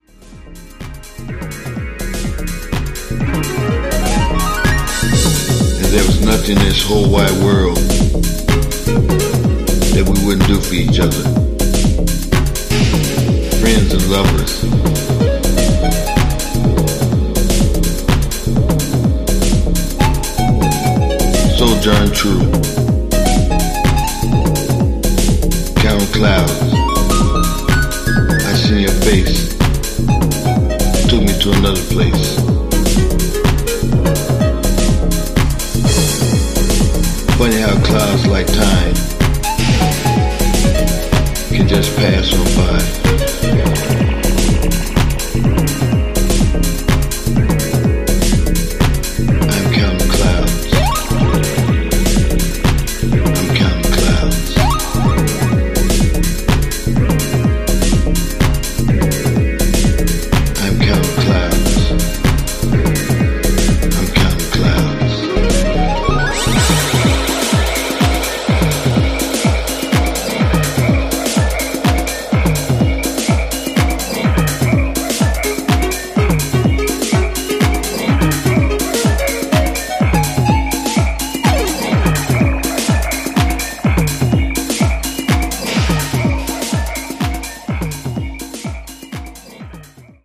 ソリッドでパーカッシヴなリズムとスペーシーなシンセ・ワーク